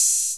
Open Hats
TR808HH1.WAV